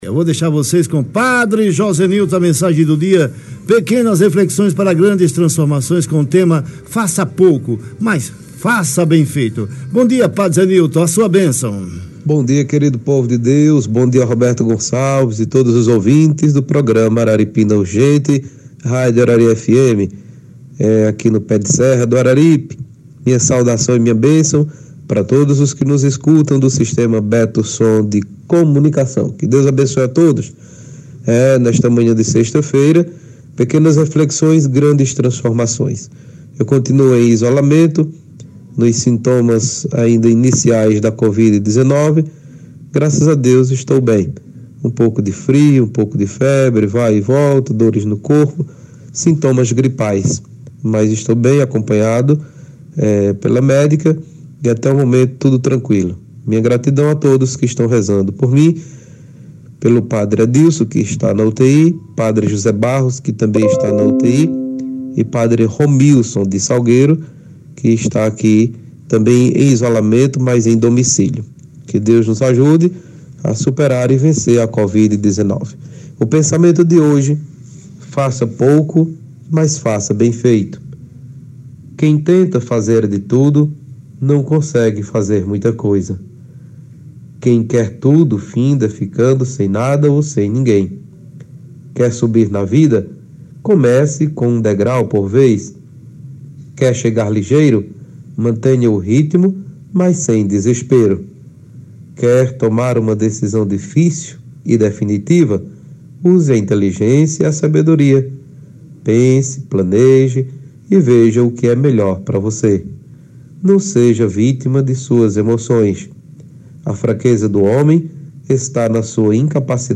na Rádio Arari FM